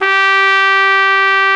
Index of /90_sSampleCDs/Roland L-CDX-03 Disk 2/BRS_Trumpet 1-4/BRS_Tp 1 Class